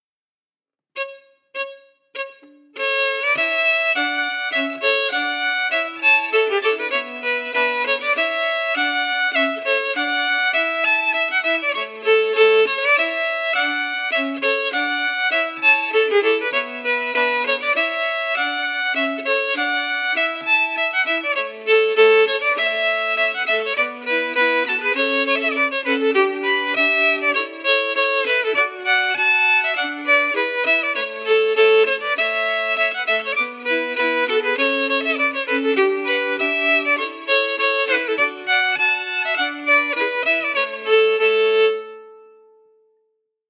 Key: A
Form: March
Melody emphasis
M: 4/4
Genre/Style: Scottish (pipe) March